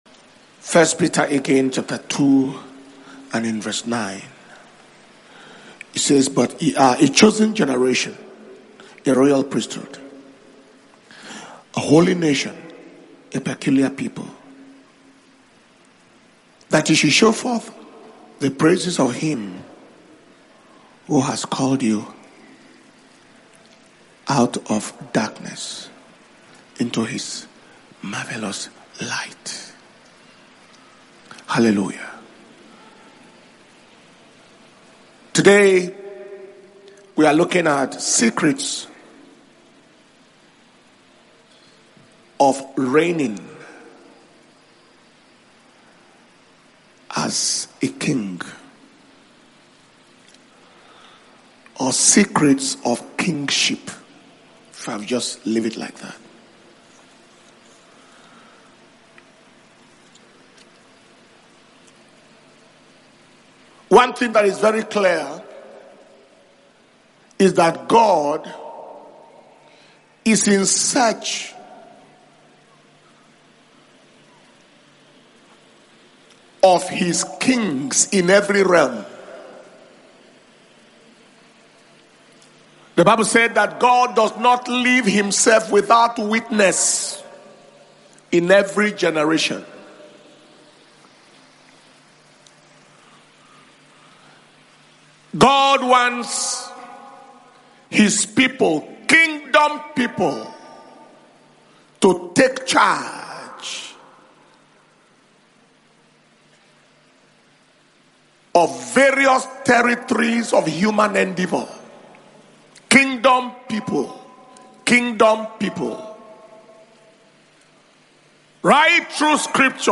KPGWC2025 – Day 2 Morning Session
Dunamis Kingdom Power And Glory World Conference 2025 – KPGWC2025